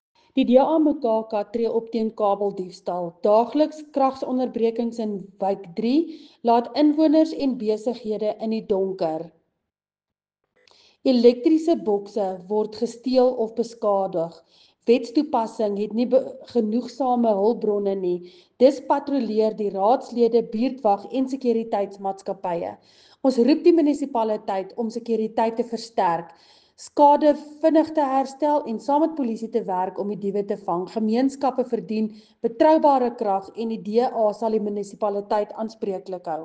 Afrikaans soundbites by Cllr Linda Louwrens and